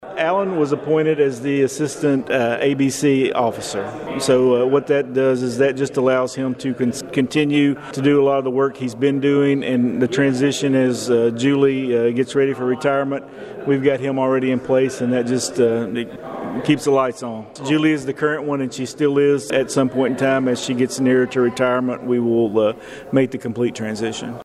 The Princeton City Council met in regular session at 5:00 pm on Monday afternoon.